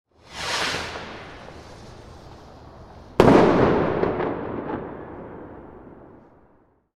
Sound Effects / Street Sounds 23 Nov, 2025 Launching And Exploding Firework Sound Effect Read more & Download...
Launching-and-exploding-firework-sound-effect.mp3